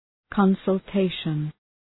Προφορά
{,kɒnsəl’teıʃən}